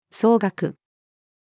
奏楽-female.mp3